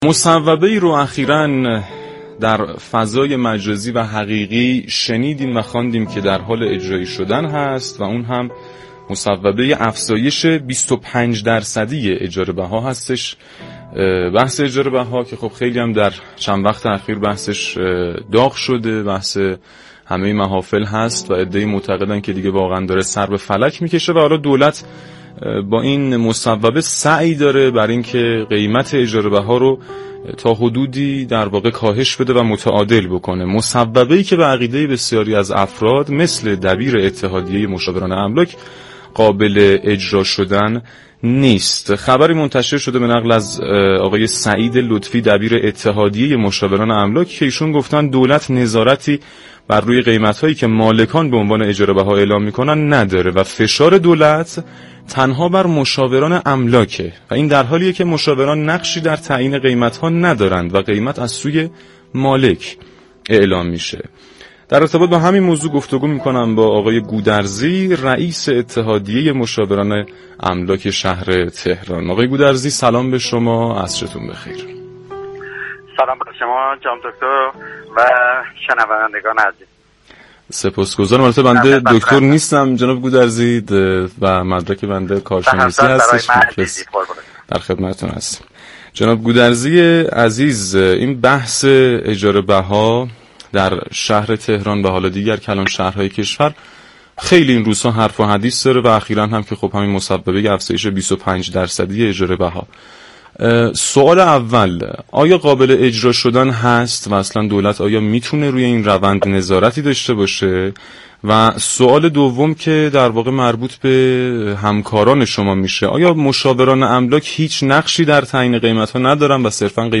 در گفت و گو با «تهران من»